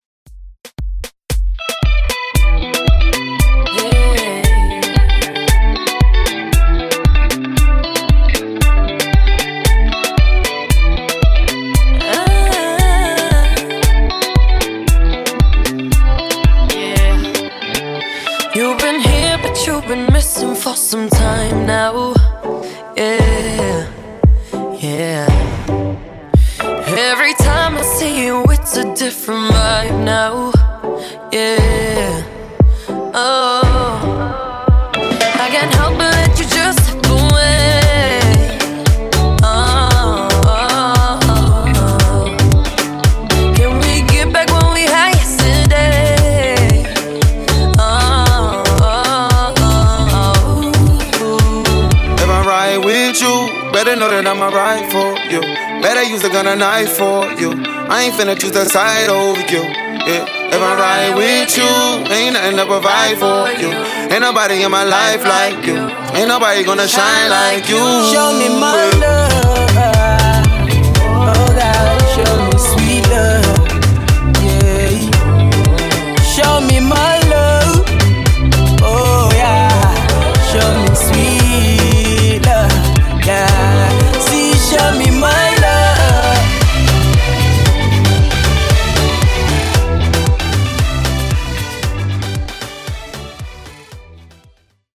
In-Outro Moombah)Date Added